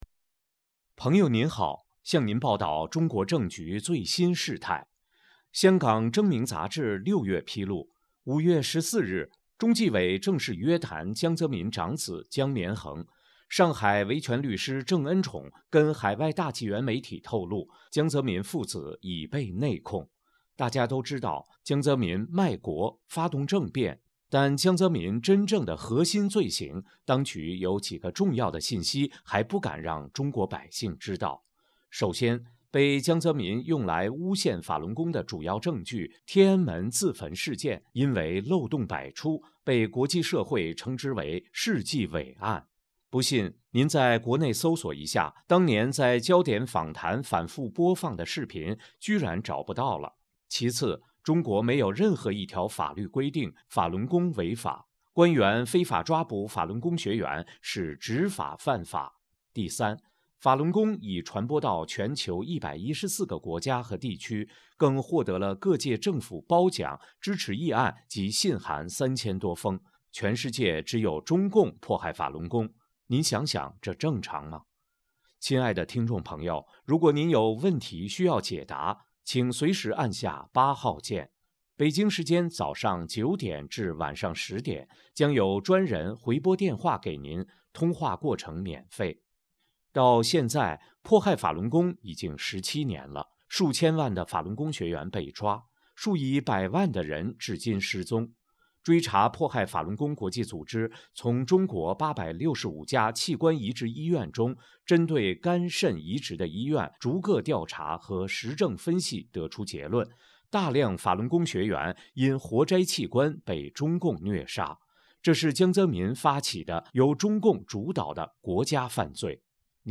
RTC真相语音电话：中国政局最新态势